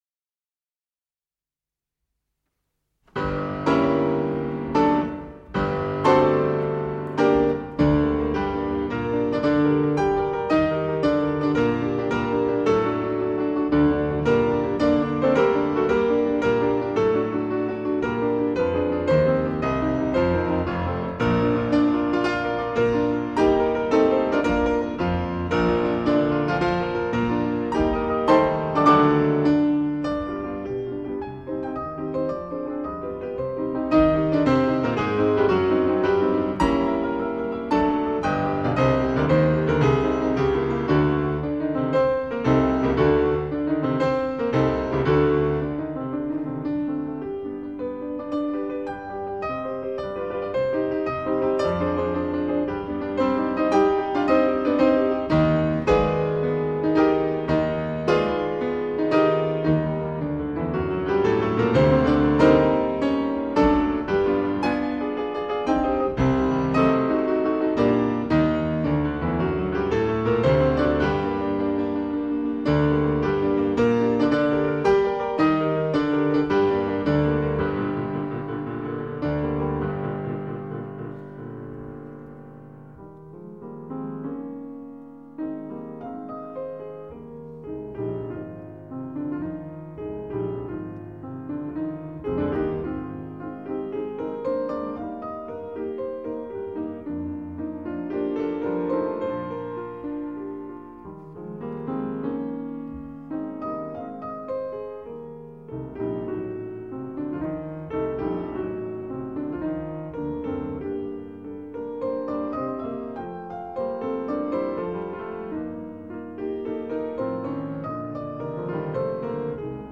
March for piano in G minor (Sehr kr?ftig), Op. 76/2